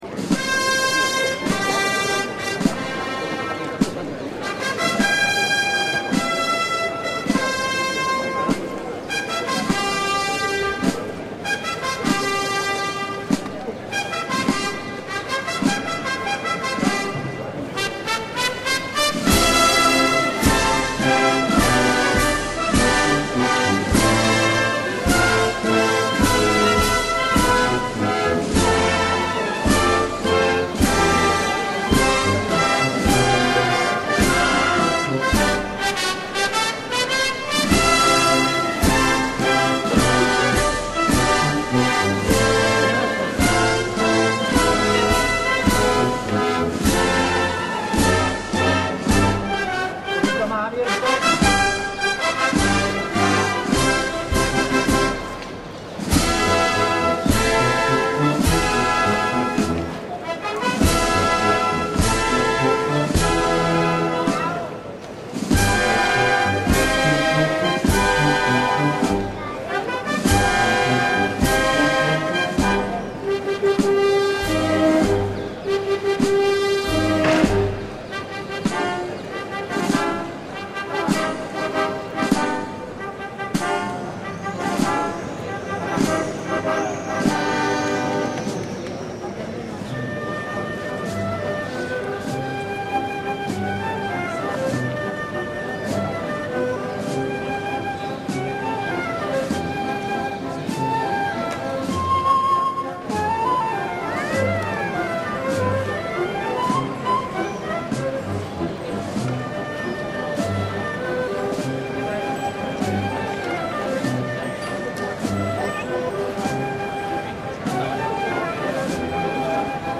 Hay que ver las fotograf�as con esta banda sonora reflejando el ambiente de las calles pulsando el logo verde y volver a la ventana de las im�genes.
marcha+ambiente.mp3